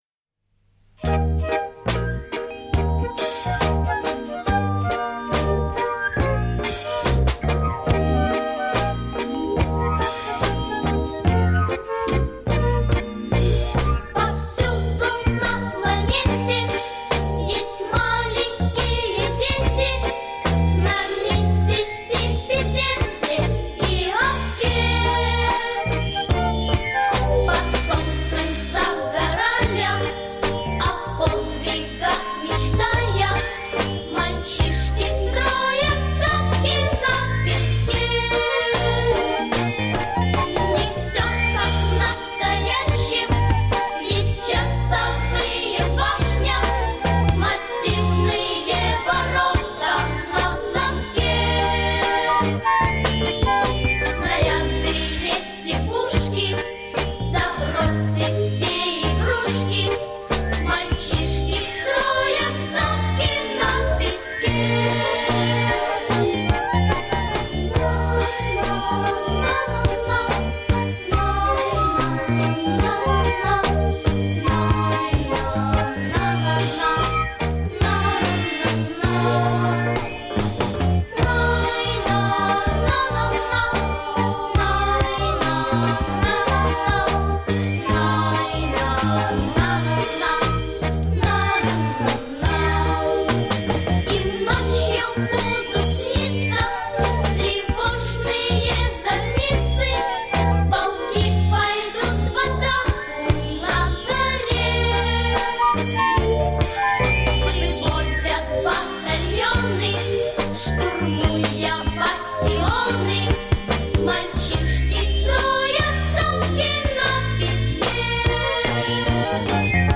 • Категория: Детские песни
советские детские песни